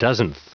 Prononciation du mot dozenth en anglais (fichier audio)
Prononciation du mot : dozenth